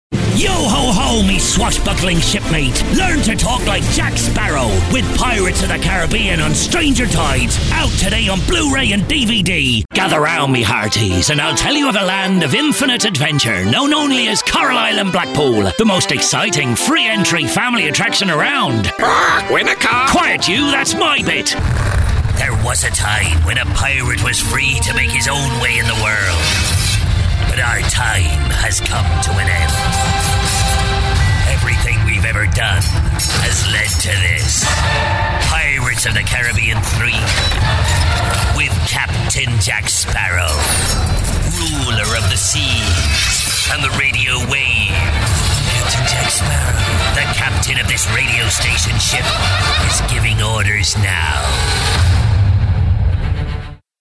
Pirate Voice – Authentic Swashbuckling | VoiceoverGuy
Voice of a Pirate on CBBC
Inspiration taken from Captain Barbossa of the Disney Pirates of the Caribbean Movies.